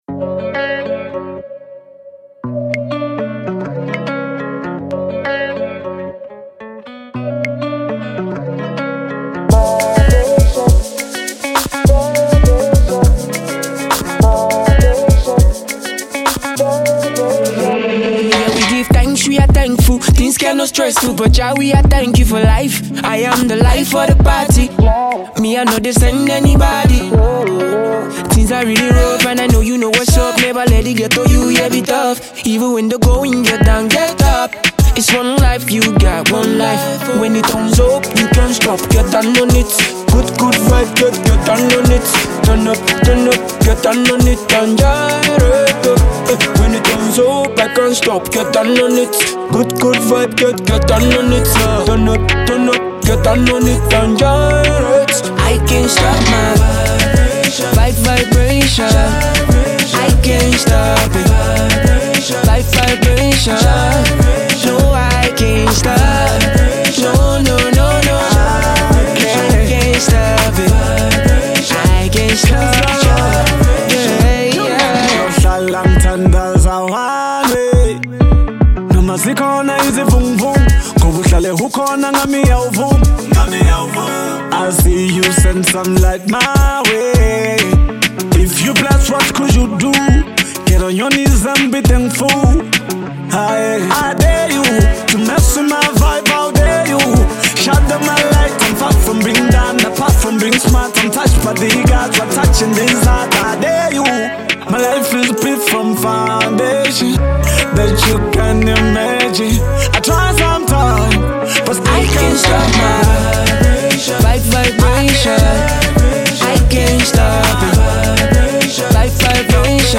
Multi-talented singer
two of South Africa’s hip-hop singers